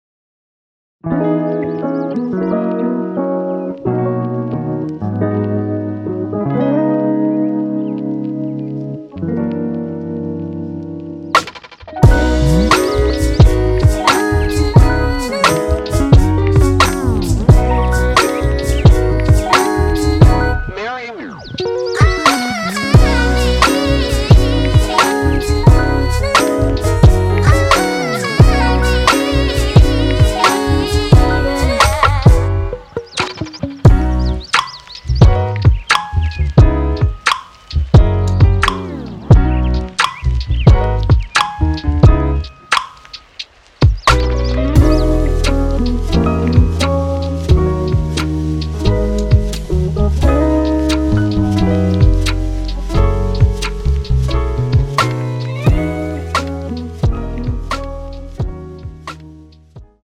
공식 음원 MR
앞부분30초, 뒷부분30초씩 편집해서 올려 드리고 있습니다.